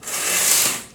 Curtain Open Sound
household
Curtain Open